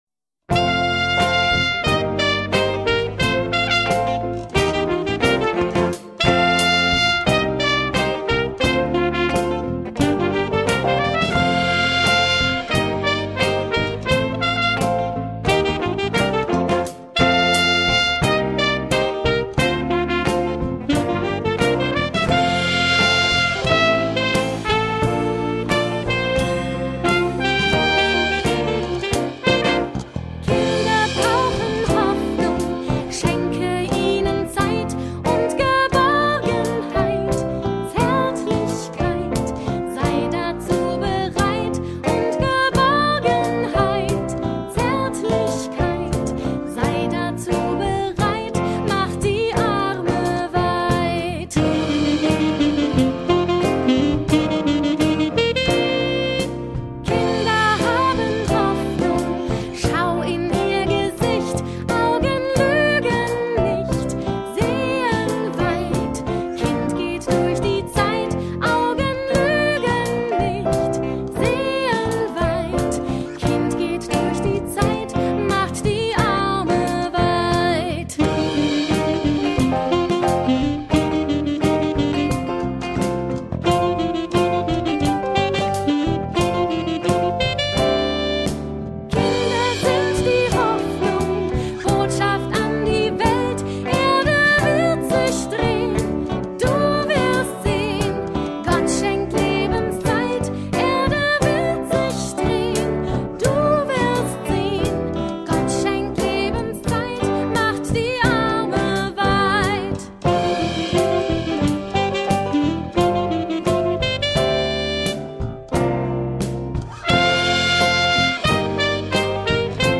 Ausführende Musiker
(Gesang, Akustik-Gitarre)
(E-Gitarre)
(E-Bass)
(Schlagzeug)
(Trompete)
(Saxofon)
(E-Klavier, Background-Gesang)
Ev.-luth. Paulusgemeinde Langenhagen.